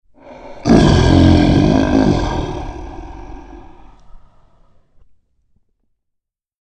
Lion_roar